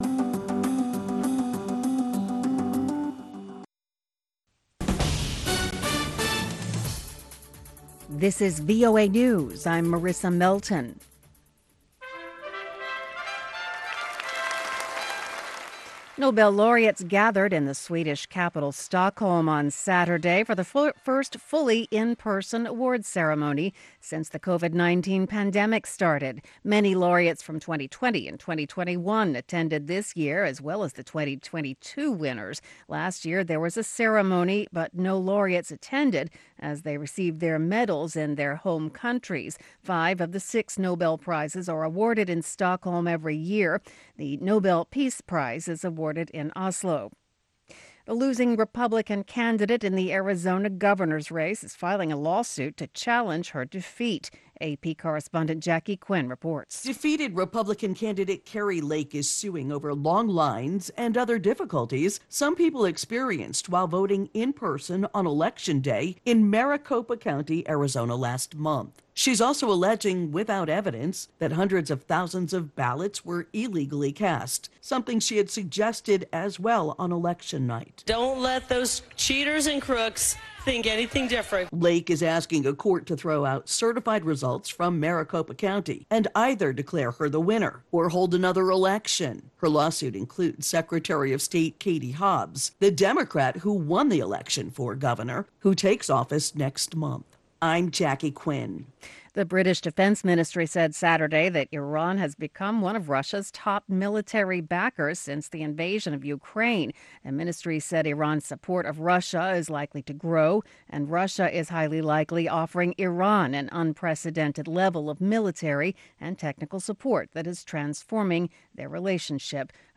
Around the clock, Voice of America keeps you in touch with the latest news. We bring you reports from our correspondents and interviews with newsmakers from across the world.